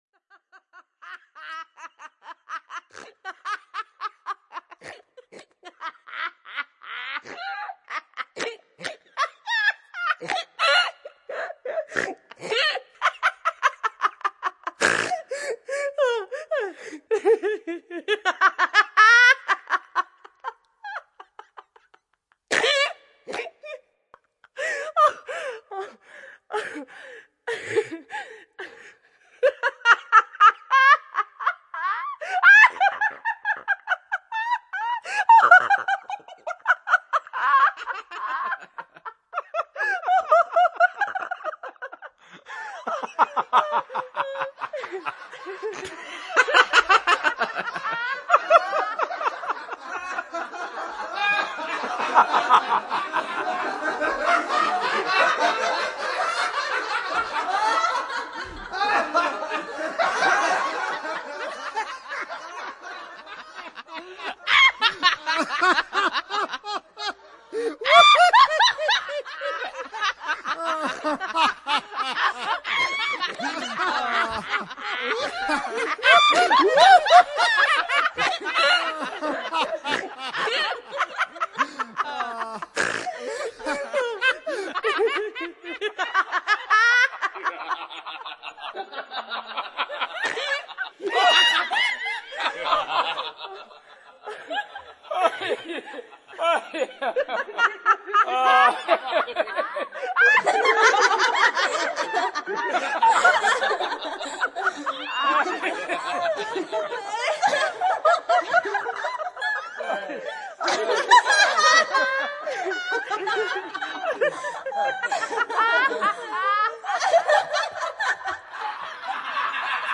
XXV Bienal de São Paulo (2002)
Risadas-compactado.mp3